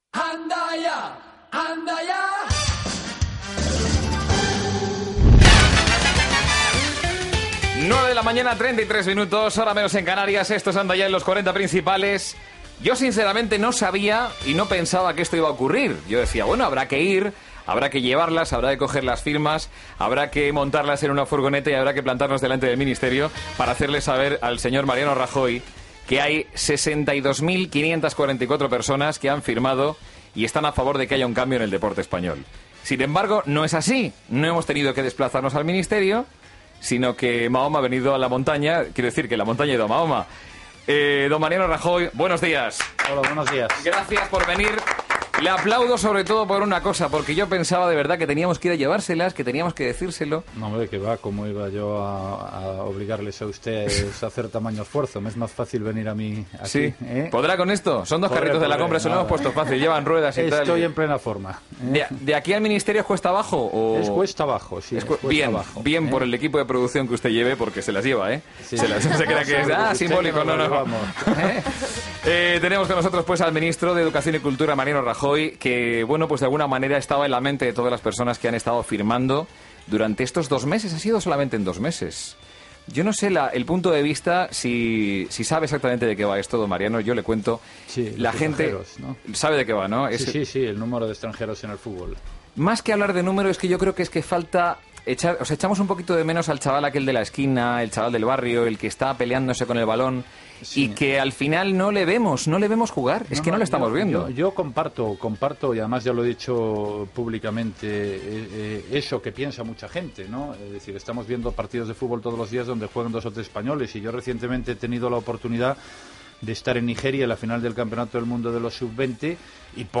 Indicatiu del programa, hora, identificació, entrevista al ministre espanyol d'Educación y Cultura Mariano Rajoy sobre la campanya que ha fet el programa en favor dels jugadors espanyols, número 1 de la llista
Entreteniment
FM